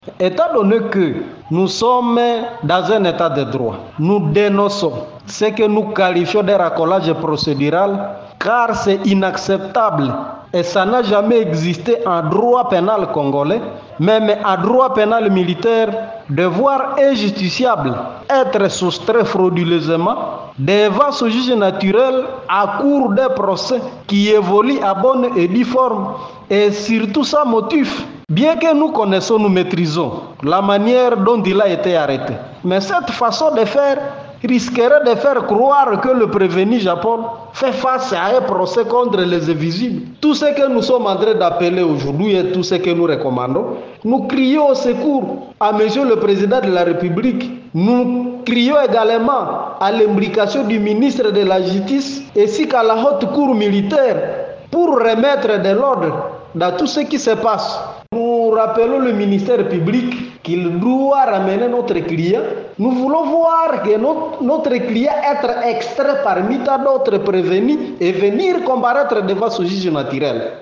Dans une interview accordée à Radio Moto Oicha ce mercredi 18 septembre 2024